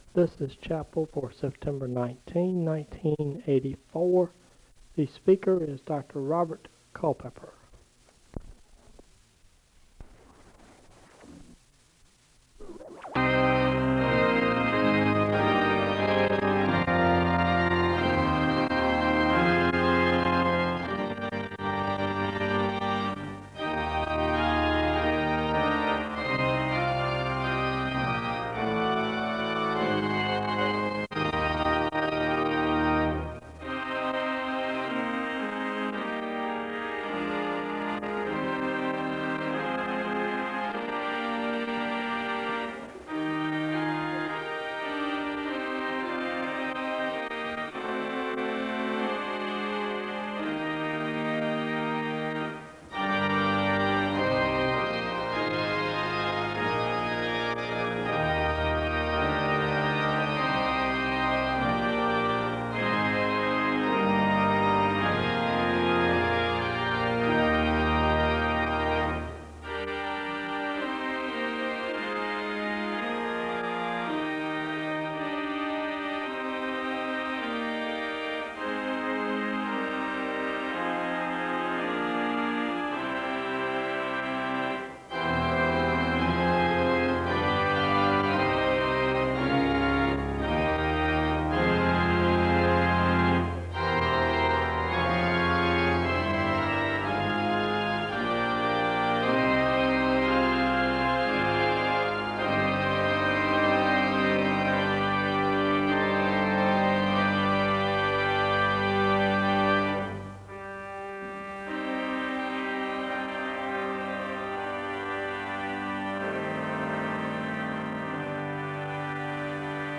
The service begins with organ music (00:00-02:10).
The choir sings a song of worship (04:07-07:00).
SEBTS Chapel and Special Event Recordings SEBTS Chapel and Special Event Recordings